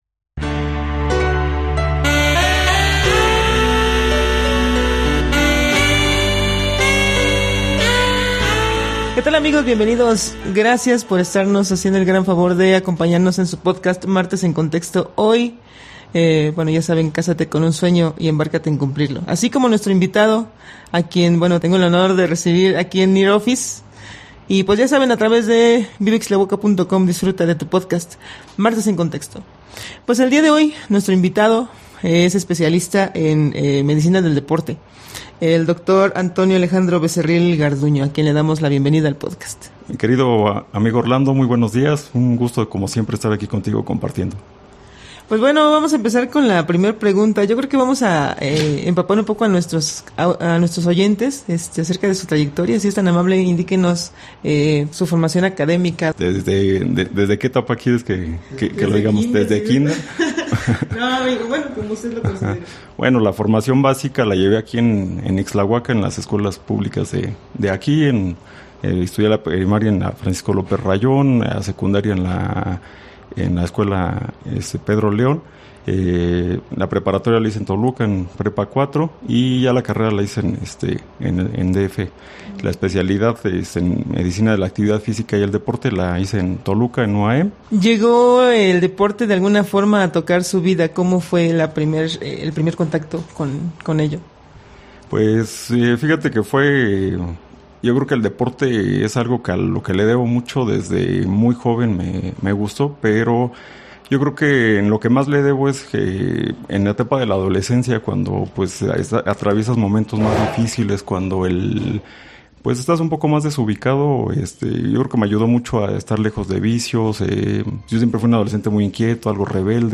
especialista en medicina del deporte.
Locación: NEAR OFFICE.